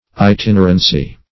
Itinerancy \I*tin"er*an*cy\ ([-i]*t[i^]n"[~e]r*an*s[y^]),1 n.